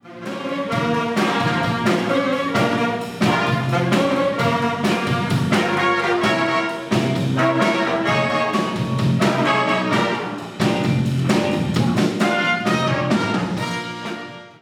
Cztery dni twórczego wysiłku, żmudnych ćwiczeń a na koniec – koncert finałowy w sali Szkoły Muzycznej im. Witolda Lutosławskiego.
jazz koncert finałowy muzyka.mp3